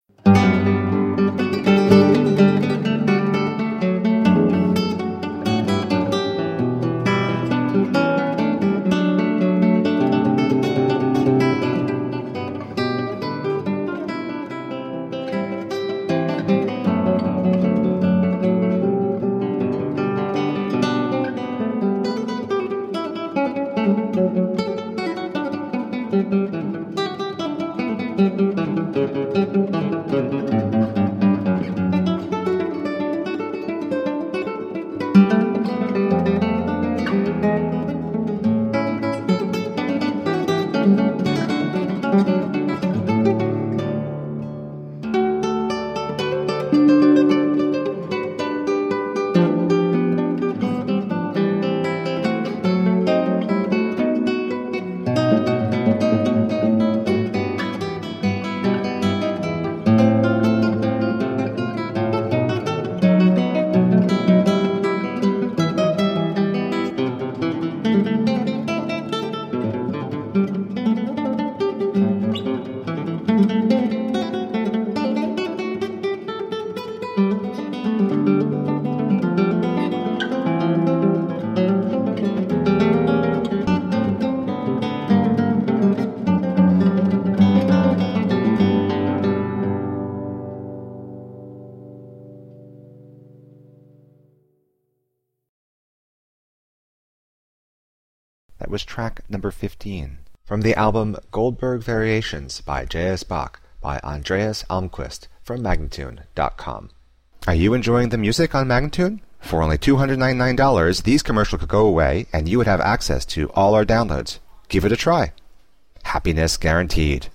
Elegant classical guitar